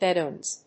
/ˈbɛdoʌnz(米国英語), ˈbedəʊʌnz(英国英語)/
bedouins.mp3